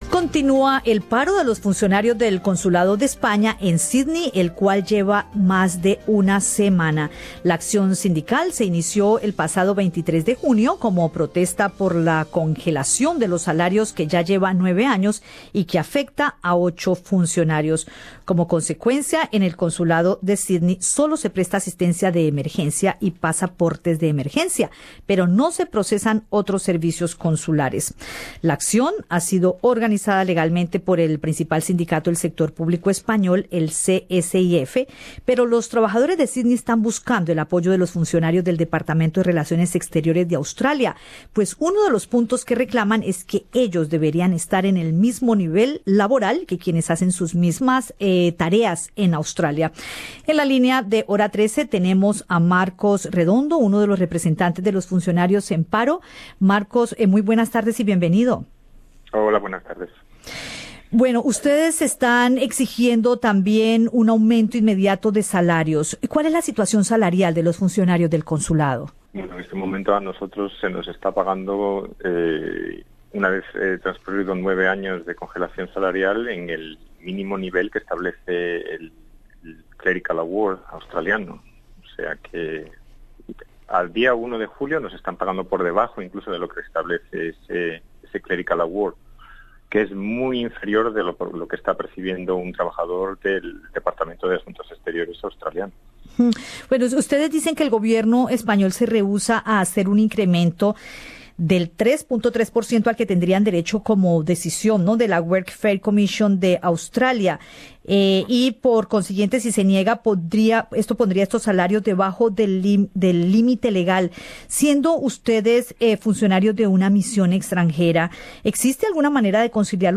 Escucha el podcast con la entrevista